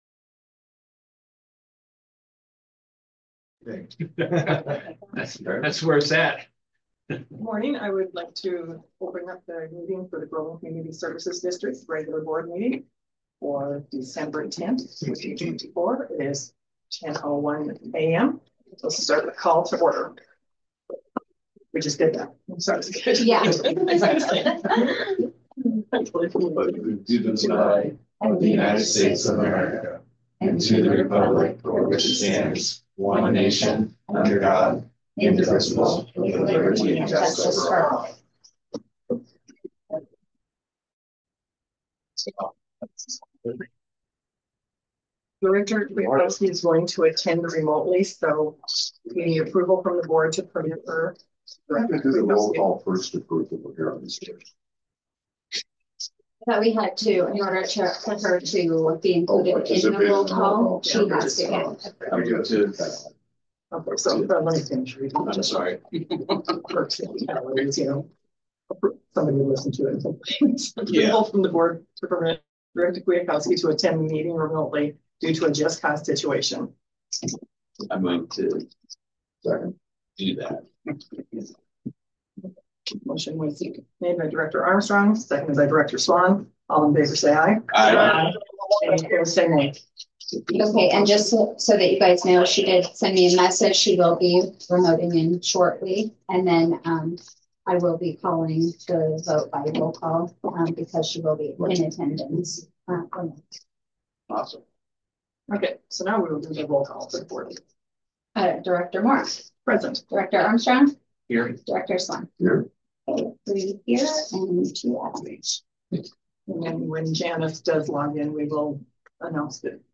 Board Regular Meeting